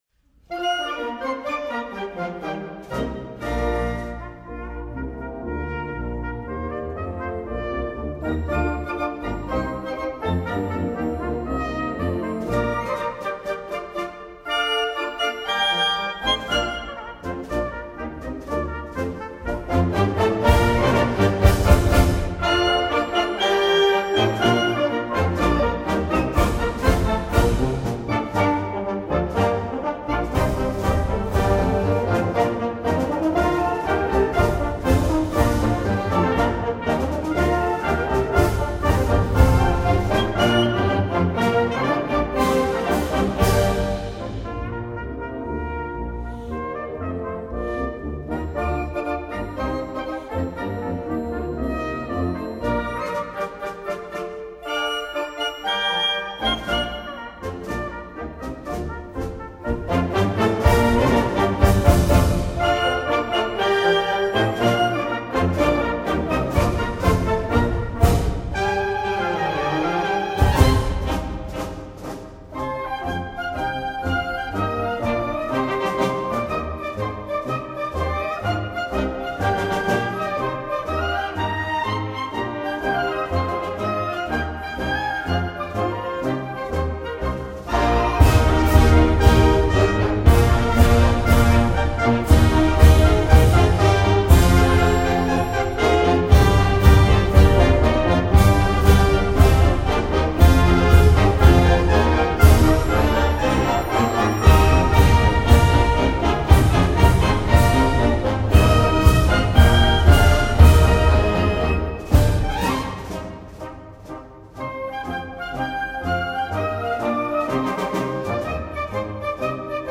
Spring! les couleurs du printemps, 3 mars 2012, Salle Dina-Bélanger